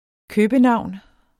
Udtale [ ˈkøːbəˌnɑwˀn ]